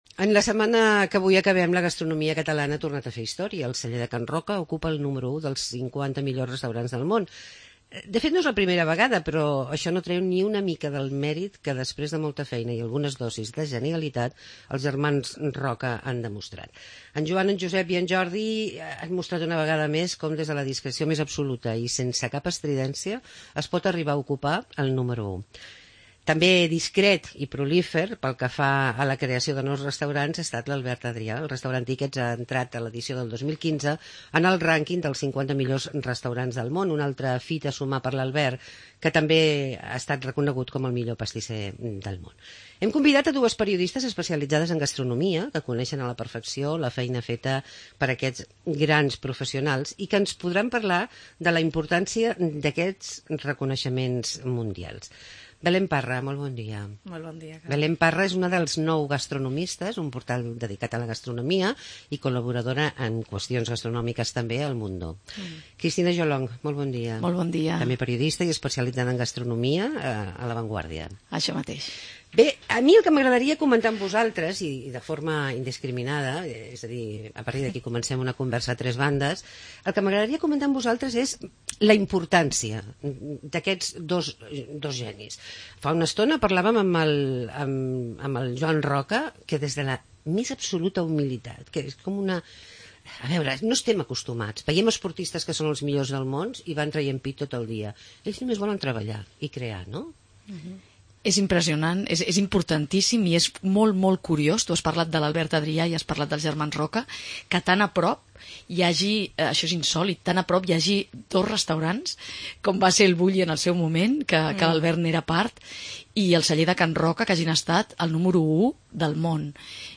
Tertulia gastronomica